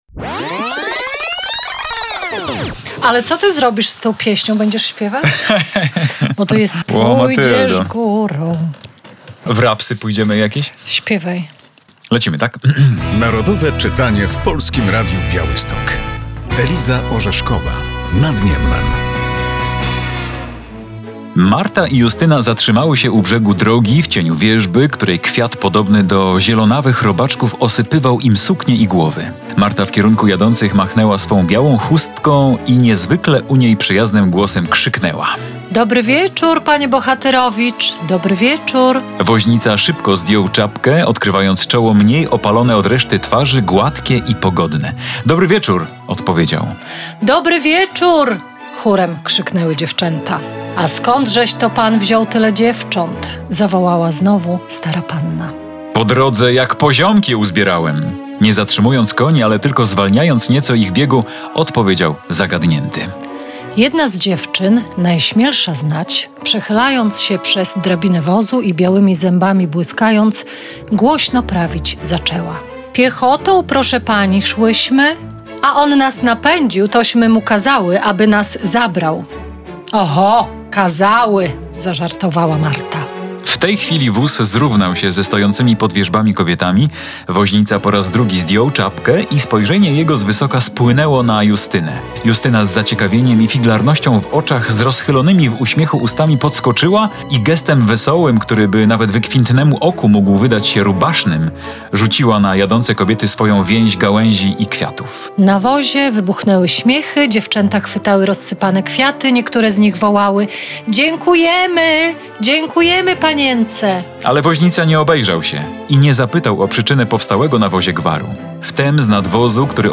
Pozytywistyczną powieść przybliża w tym roku ogólnopolska akcja Narodowe Czytanie. Włączają się do niej także dziennikarze Polskiego Radia Białystok.